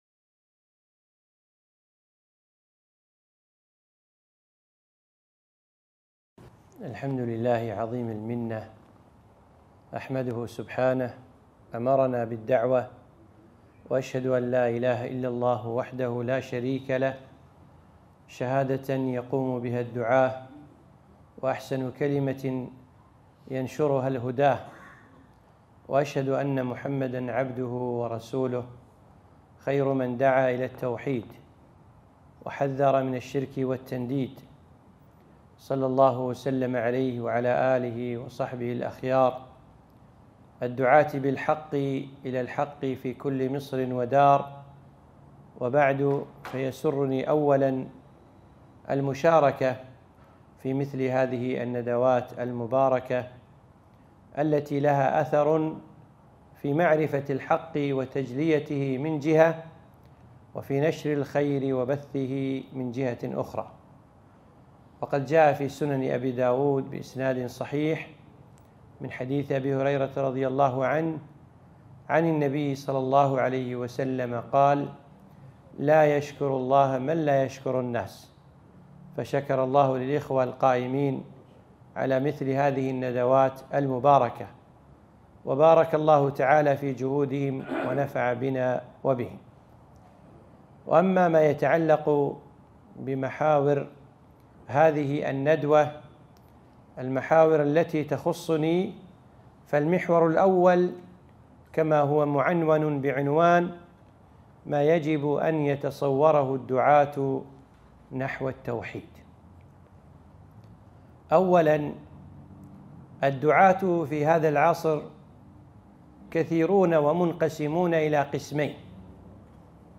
ندوة - التوحيد في مسيرة الدعوة والدعاة